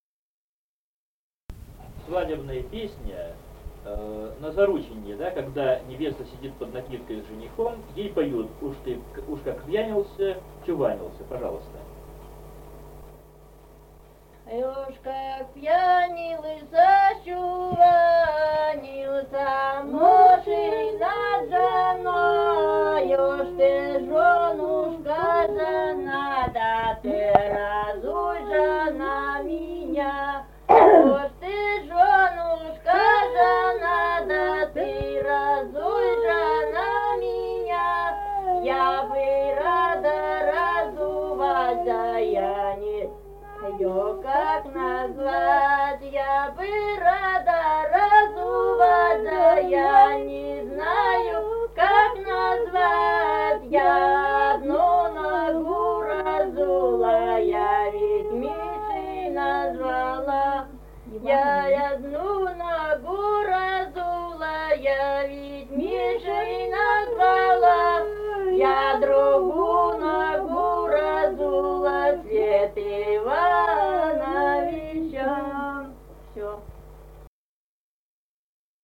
Республика Казахстан, Восточно-Казахстанская обл., Катон-Карагайский р-н, с. Белое, июль 1978.